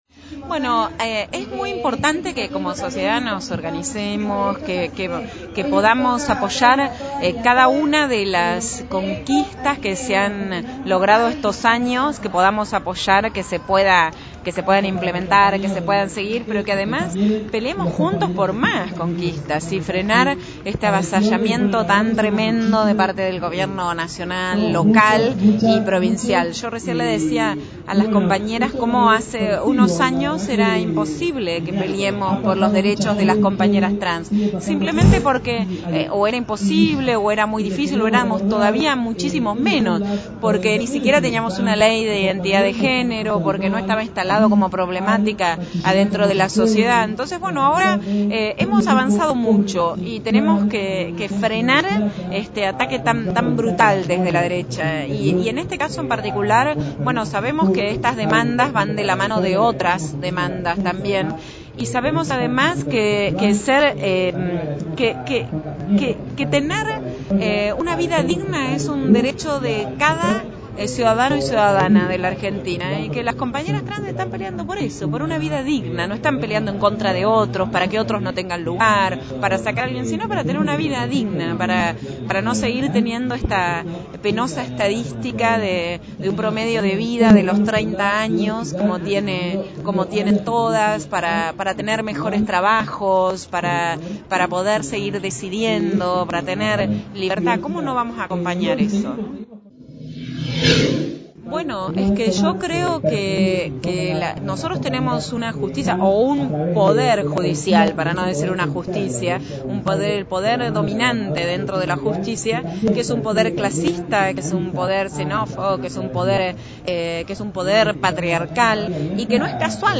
Se realizó el festival por los derechos trans en Plaza Matheu
Florencia Saintout, concejala plantense y decana de la Facultad de Periodismo